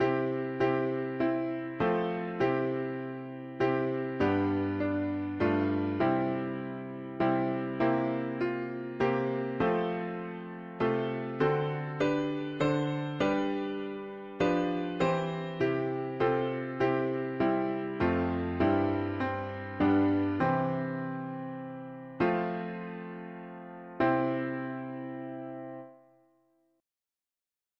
… english theist 4part chords
Key: C major Meter: 8.8.8